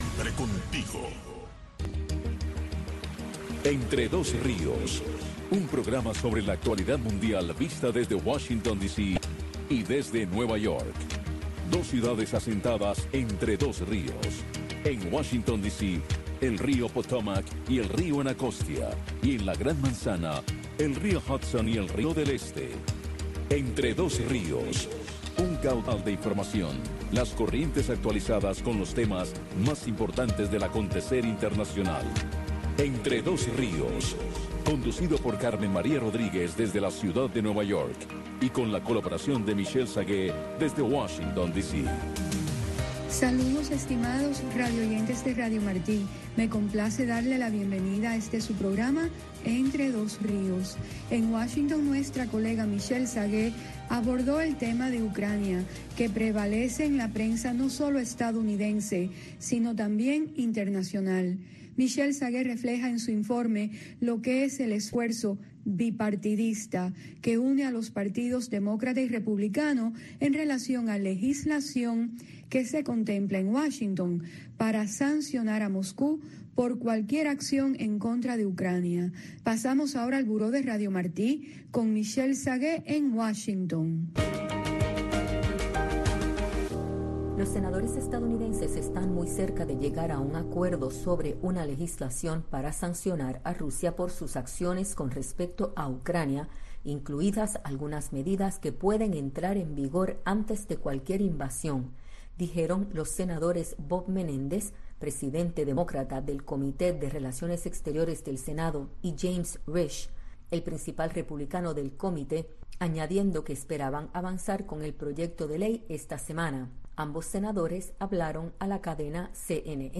una revista de entrevistas